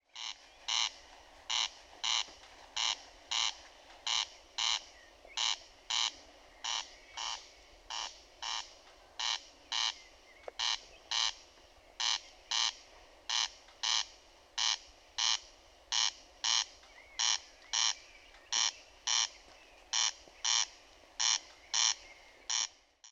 And if you haven’t heard what a calling male sounds like, here you go….it always sounds to me like someone vigorously running a thumb nail over the teeth of a comb.